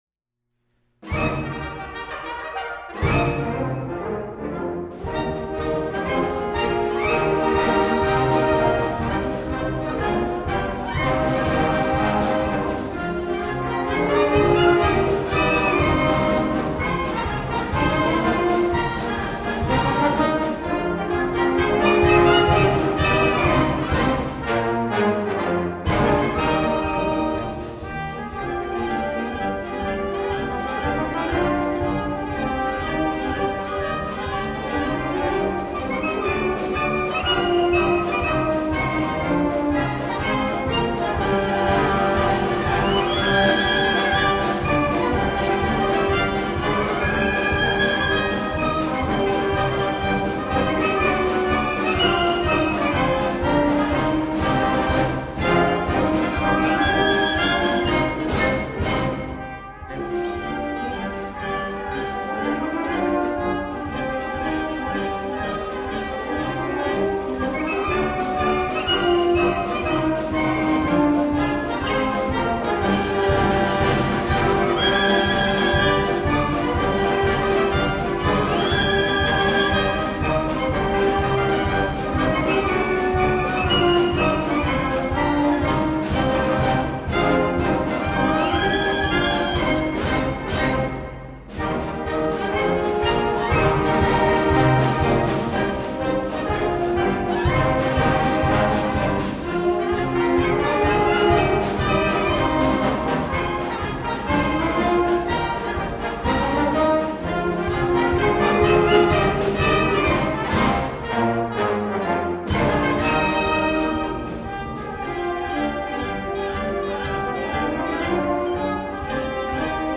Spring Concert  May 11, 2009: